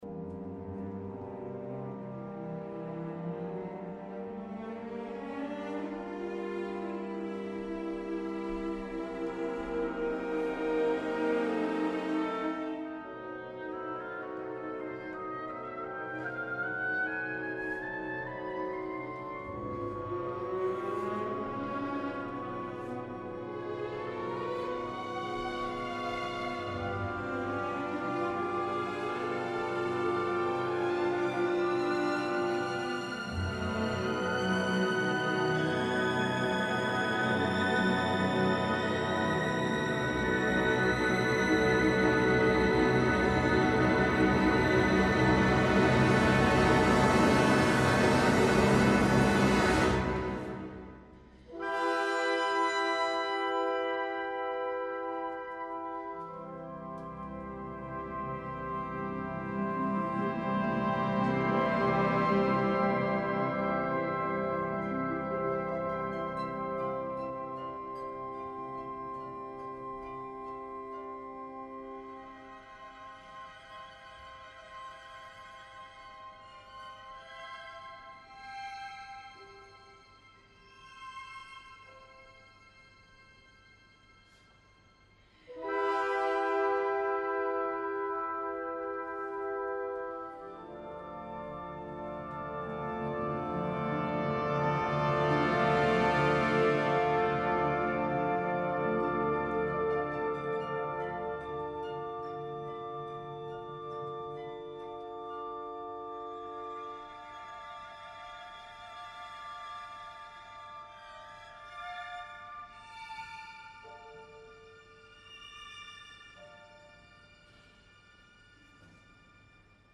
Lance Ryan ha demostrat manta vegades que no és capaç de cantar Siegfried sense acabar produint lesions auriculars i m’atreviria a dir que fins i tot psíquiques als pacients escoltadors o espectadors que han de suportar una veu ingrata, inadequada, gens heroica però que sobretot canta malament, que emet amb dificultat escanyant el flux vocal, canviant constantment de color per falsejar una vocalitat erràtica mentre portamenteja per intentar arribar a notes que sovint són calades, no recolza ni respira bé, emet sons grotescs que l’agermanen a Mime i es fa en definitiva, molt molest.
duo-final.mp3